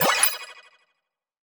Notification 3.wav